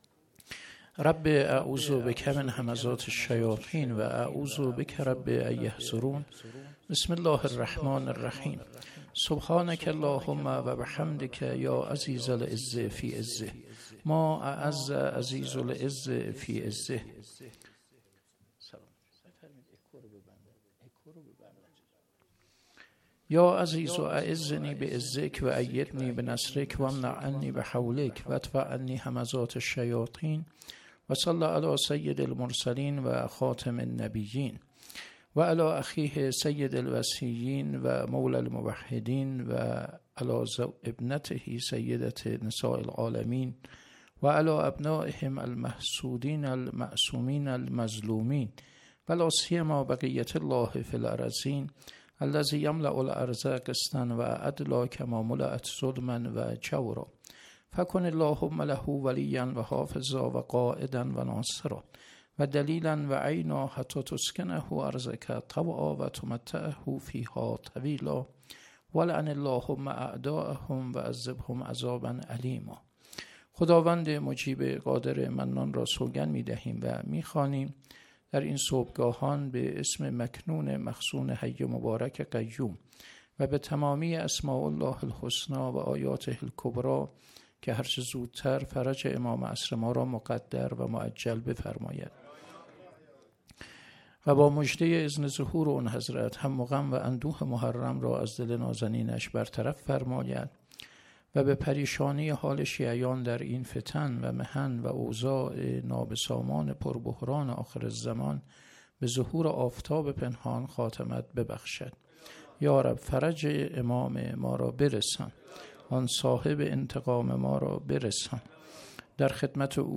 محرم1401 - شب چهاردهم محرم - سخنرانی